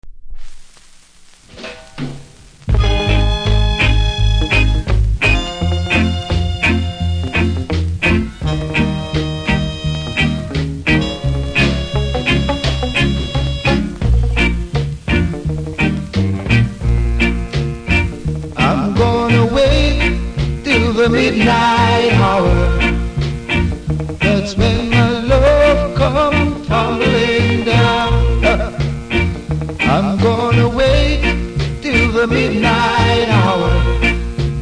プレスノイズありますが音が出れば気にならない程度。